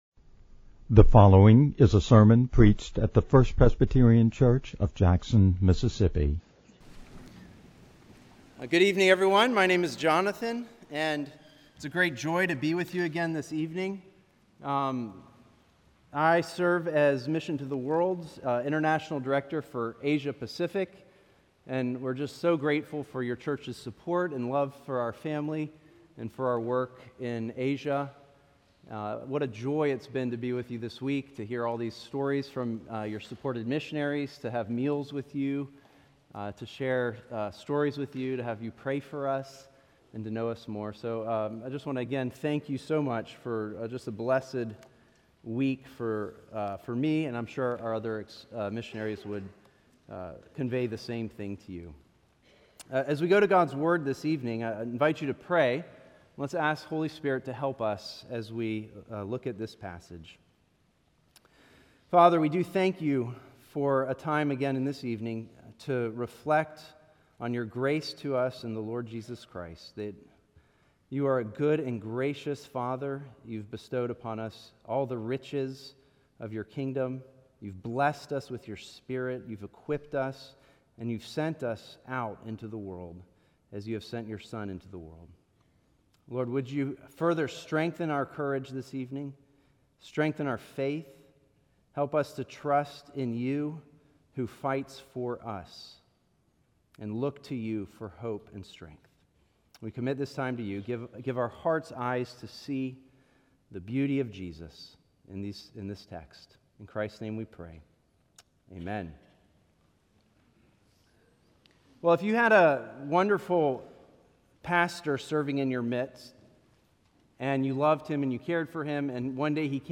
March-1-2026-Evening-Sermon-audio-with-intro.mp3